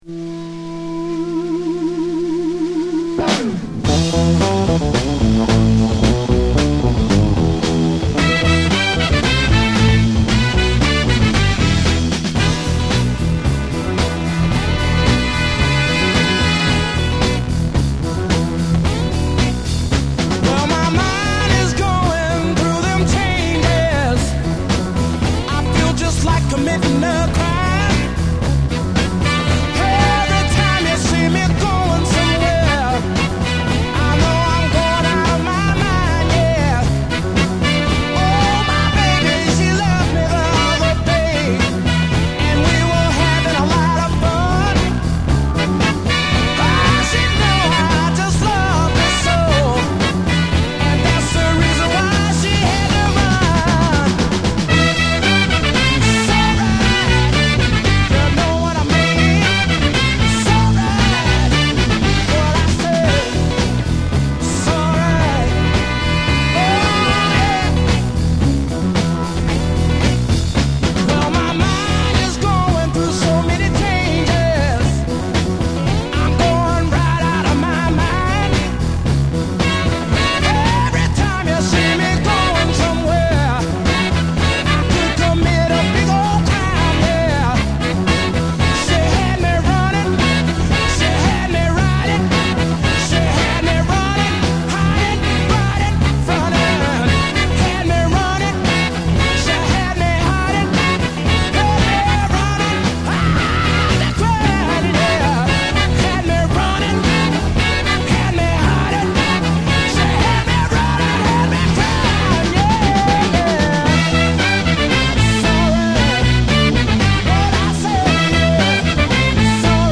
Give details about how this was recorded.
It has pristine Mint audio.